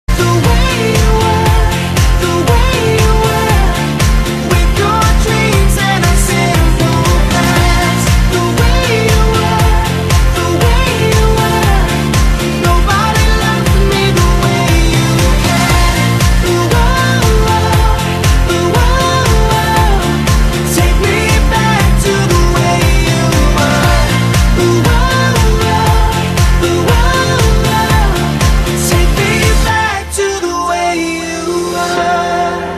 M4R铃声, MP3铃声, 欧美歌曲 68 首发日期：2018-05-15 08:30 星期二